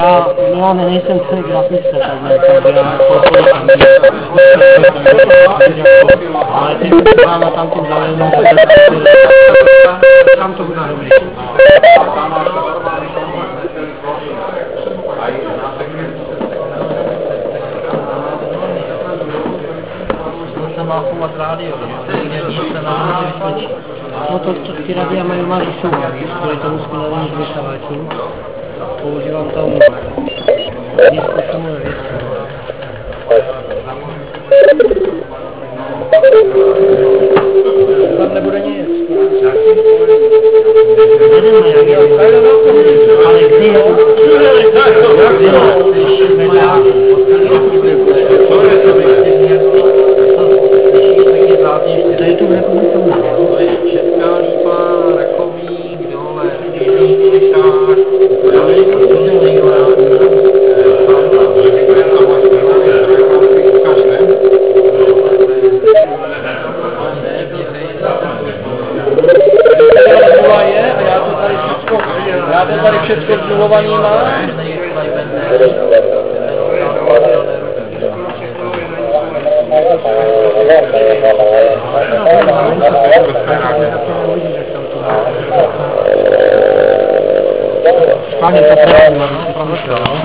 Zde pozor, vše co zde uslyšíte jsou "panelové diskuse" a občas padne i nějaké slovíčko..HI. Např. jsem měl záznam příjmu LIBRY 80, ale tam těch slovíček padlo více a tak to tady nenajdete HI.
Ocean 2005 zvuk (*.wav 360 KB)